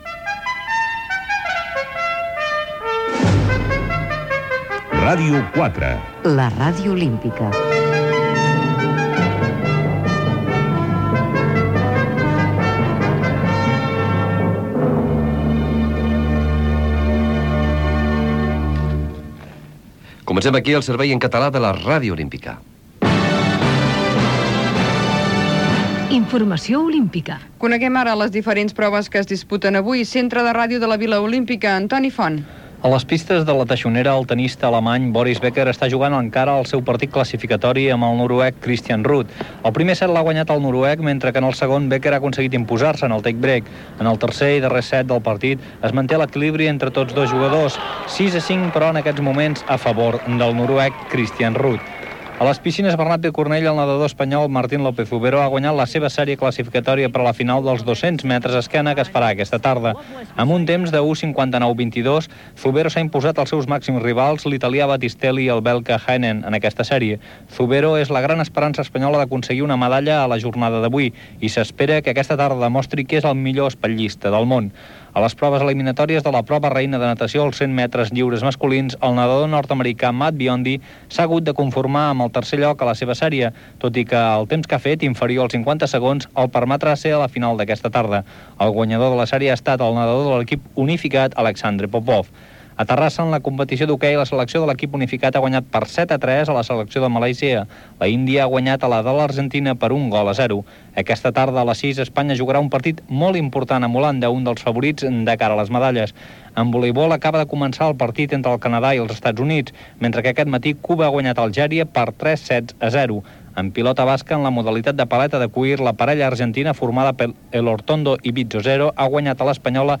Identificació de la ràdio, resum informatiu de les competicions olímpiques, tema musical, notícies internacionals, àrea de serveis (el temps i l'estat del trànsit), sintonia de la ràdio Gènere radiofònic Informatiu